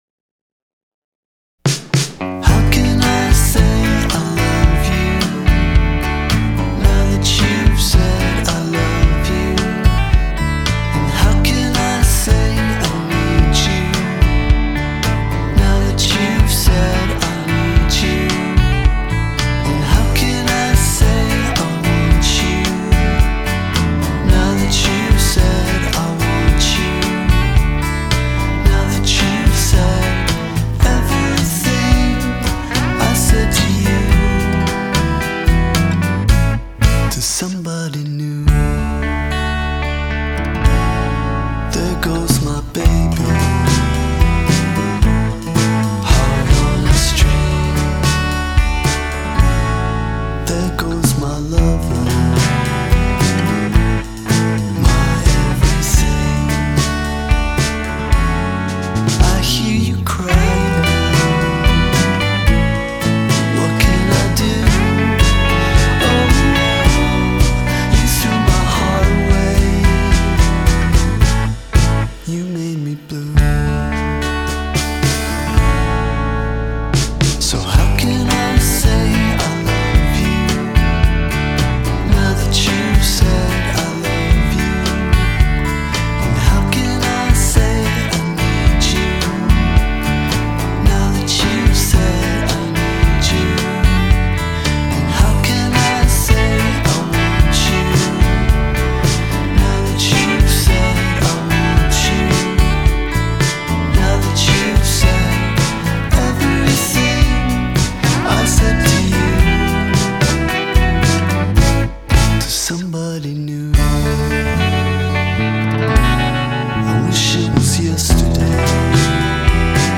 Genre: Indie, Rock